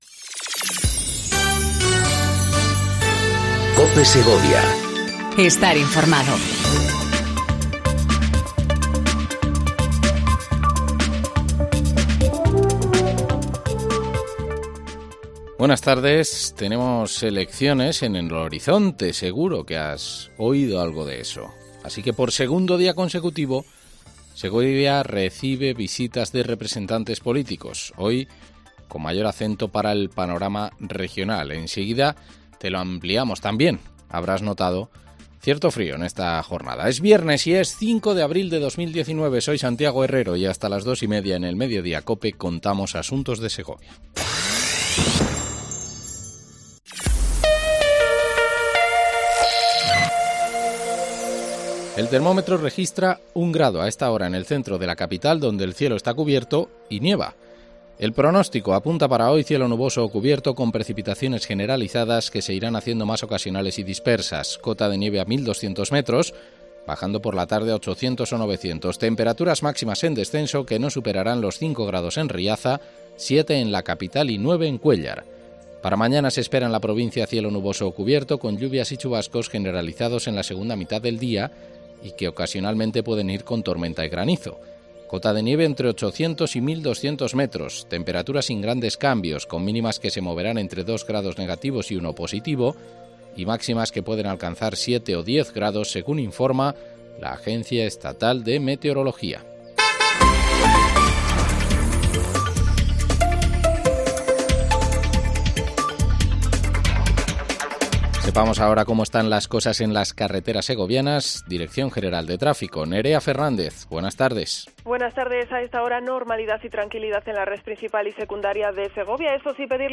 INFORMATIVO LOCAL